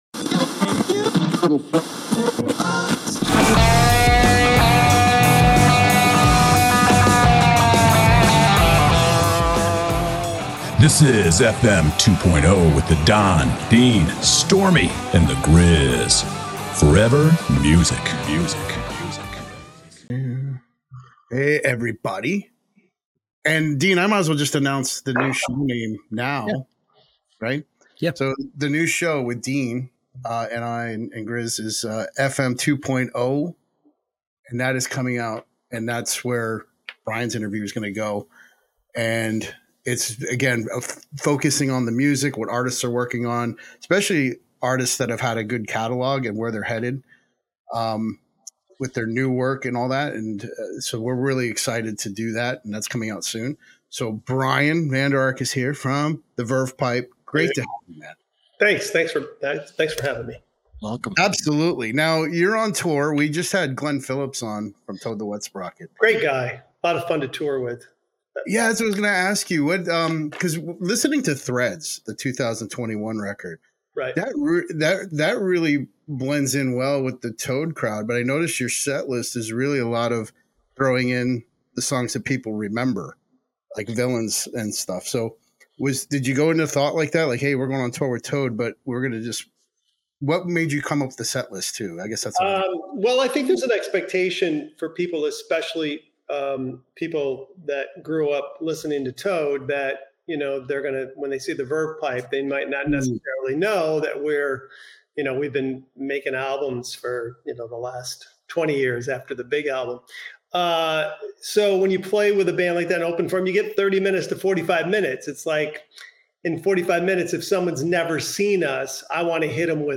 Brian Vander Ark Of The Verve Pipe: A Fun and Fascinating Interview on FM 2.0 Conversations